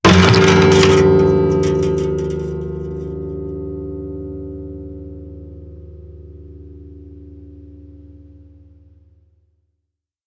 Rotura de una cuerda de una guitarra
cordófono
guitarra
Sonidos: Especiales